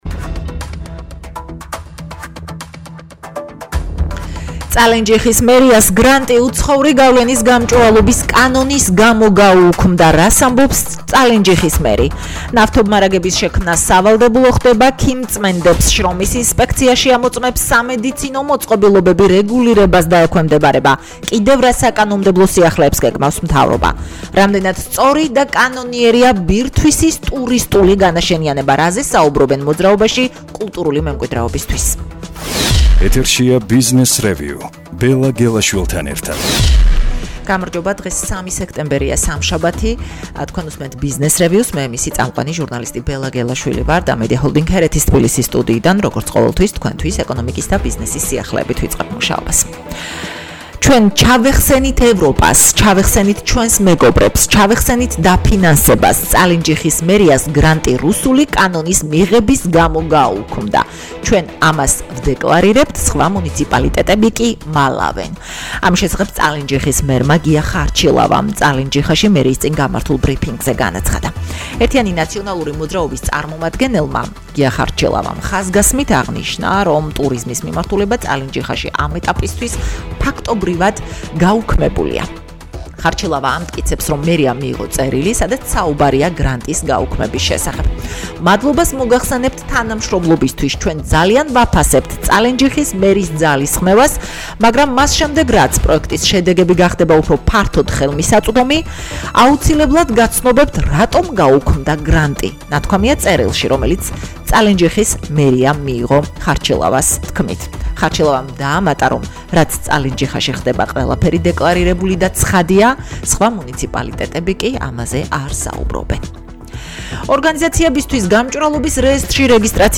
ყოველდღღე რადიოების: თბილისის FM93.5, ჰერეთის FM102.8 და ციტრუსის FM97.3 ეთერში ორშაბათიდან პარასკევის ჩათვლით 10:10 და 21:00 საათზე.